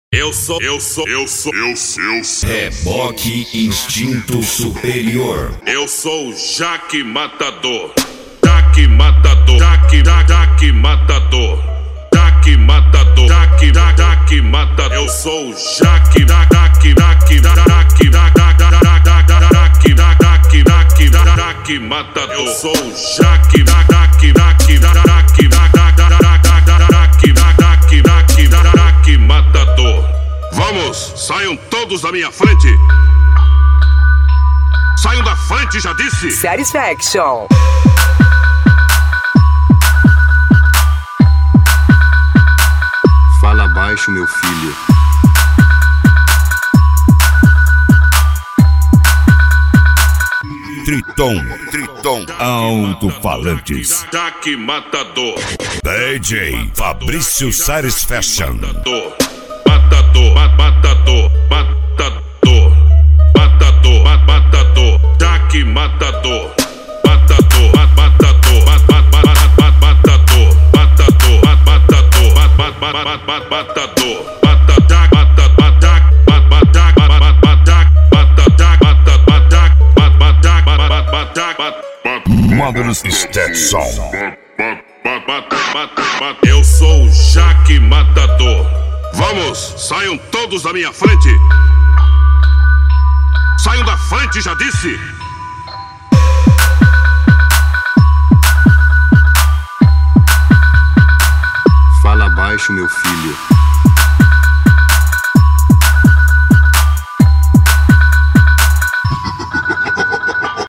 Bass
Mega Funk